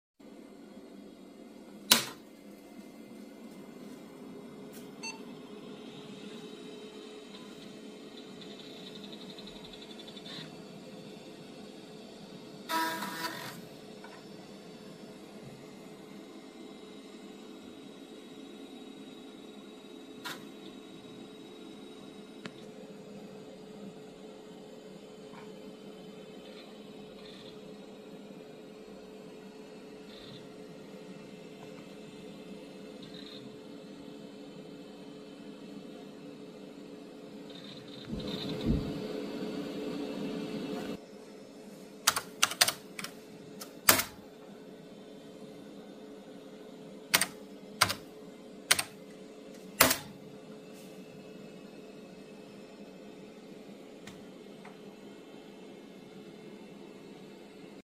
It's 1995 & you startup sound effects free download
It's 1995 & you startup a 486DX 66mhz 486DX 66 mhz i486 by Intel MS-DOS 6.20 SoundBlaster 16 Isa sound card